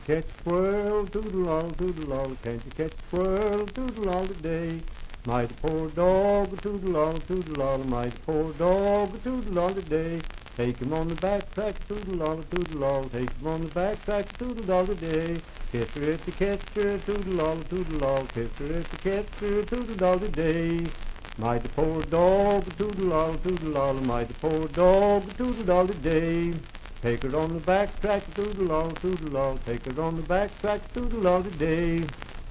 Unaccompanied vocal music
Dance, Game, and Party Songs
Voice (sung)
Franklin (Pendleton County, W. Va.), Pendleton County (W. Va.)